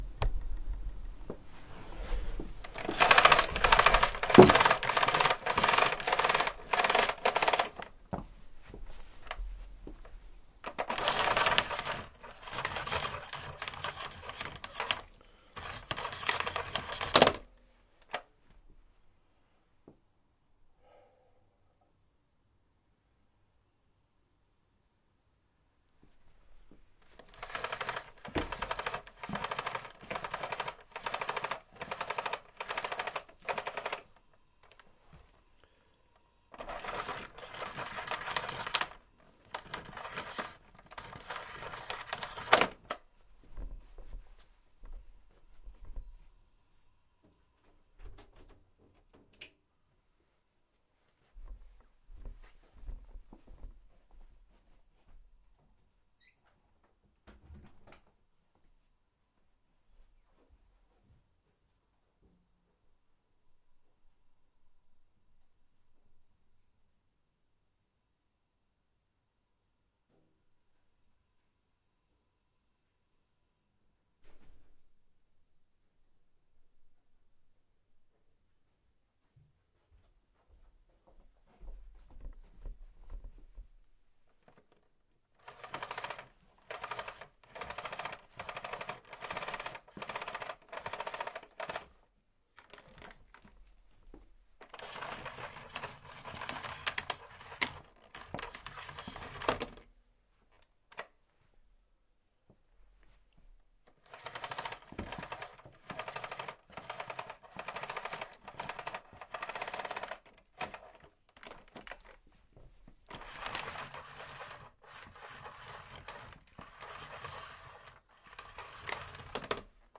描述：工作完成后，将胡佛电缆卷起来